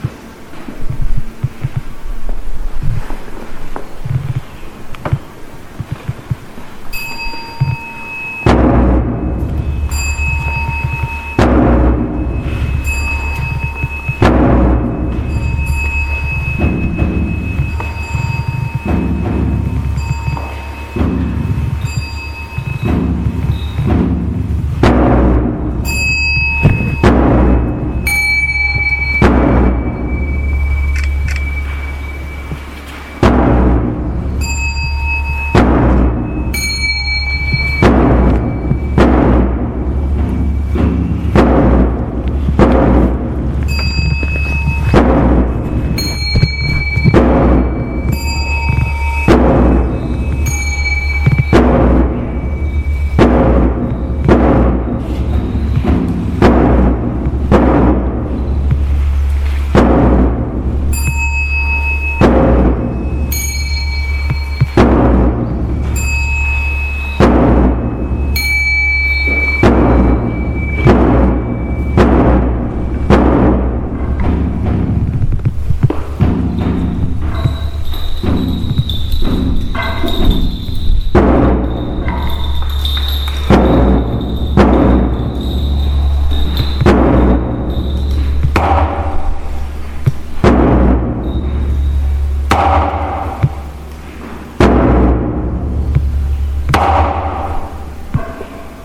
～磬子と引鏧（終） ◆祈りの聲・経のひとしずく 鳴り物 力強い大太鼓の音と透き通るような引鏧の音が絶妙に調和して響き、吊るされている垂れ幕の鈴が風に吹かれ微かに色を添える。 香讃 引鏧、太鼓、木魚、半鐘等鳴り物に合わせて梵唄が緩やかに唱えられる。
…南無香雲蓋菩薩摩訶薩（なんむひゃんいんかいぷさもほさ） 読経 木魚と半鐘と共に唐音で唱えられるお経。
終わりの磬子と引鏧 最後に磬子（けいす）と引鏧が重なり合って鳴り響き静かに終わる。
多彩な鳴り物、そして時に激しく、時に深く静かに鳴り響く その音色。
そのお経も唐音と呼ばれる伝来当時の中国の発音。